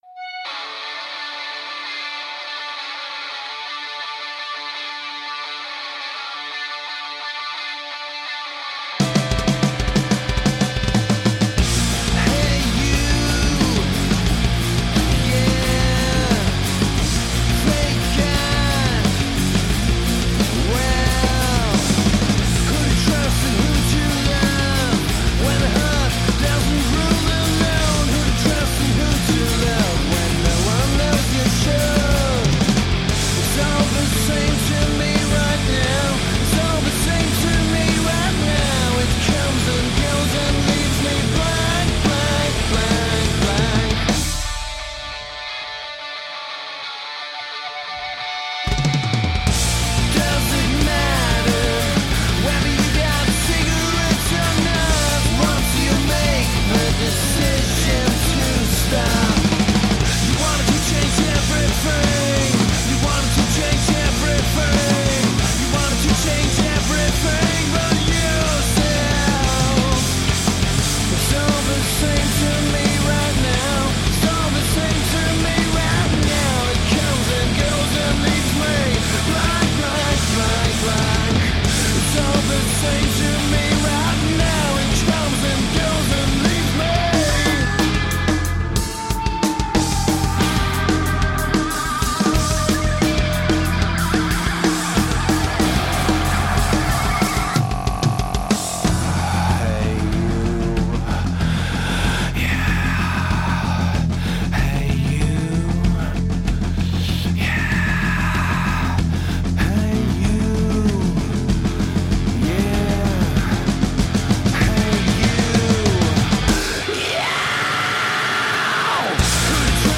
Junge Menschen die laut sind.
Die Garage wird heller.